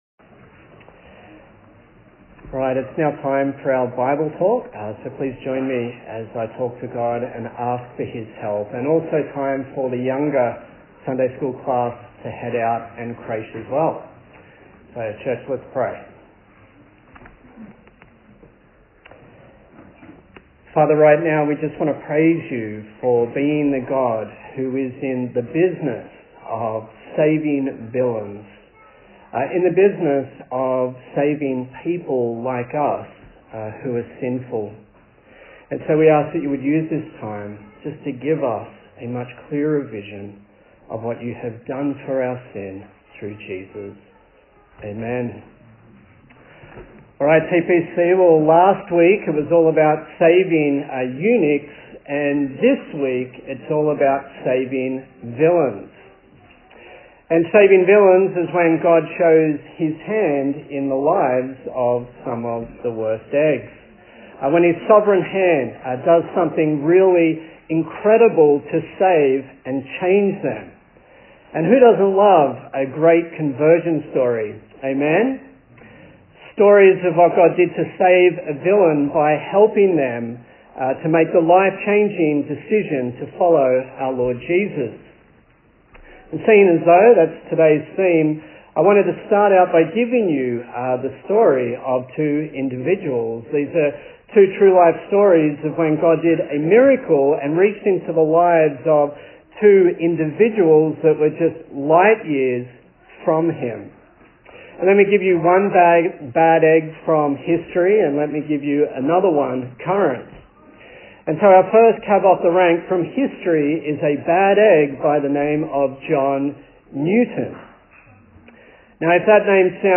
Acts Passage: Acts 9:1-31 Service Type: Sunday Morning